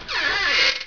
door_open_1.ogg